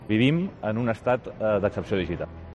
Declaraciones de Jordi Puigneró